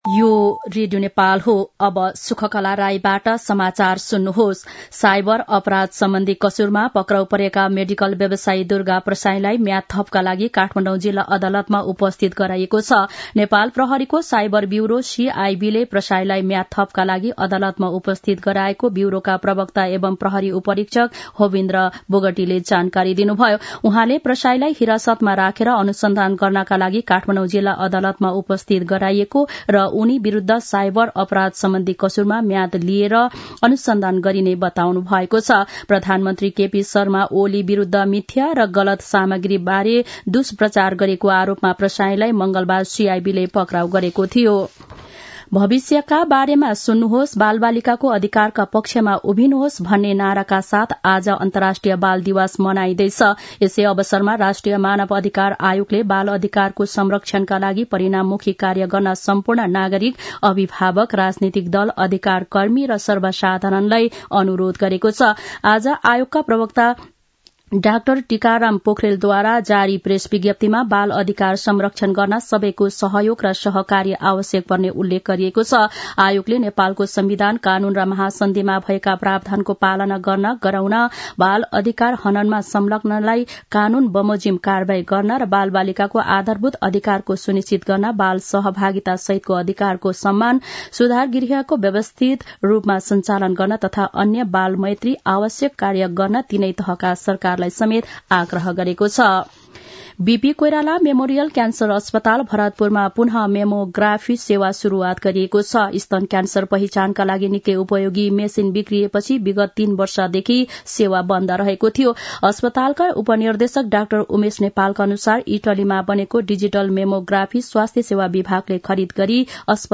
दिउँसो १ बजेको नेपाली समाचार : ६ मंसिर , २०८१
1-pm-nepali-news-1-4.mp3